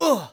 xys受伤2.wav 0:00.00 0:00.34 xys受伤2.wav WAV · 29 KB · 單聲道 (1ch) 下载文件 本站所有音效均采用 CC0 授权 ，可免费用于商业与个人项目，无需署名。
人声采集素材